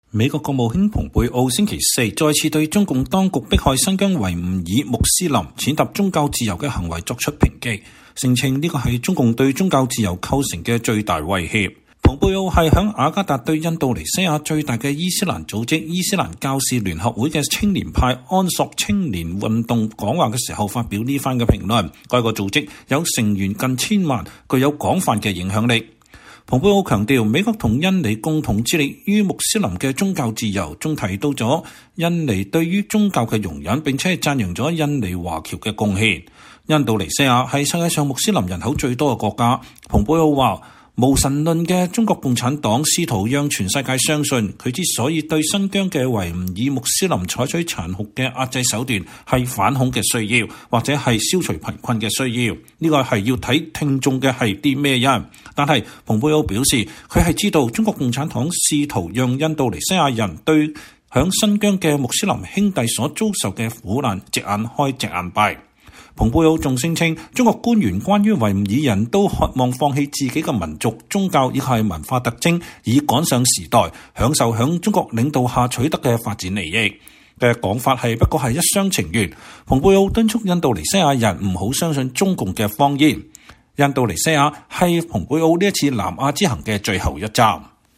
美國國務卿蓬佩奧在印度尼西亞向伊斯蘭組織“伊斯蘭教士聯合會”青年派安索青年運動發表講話。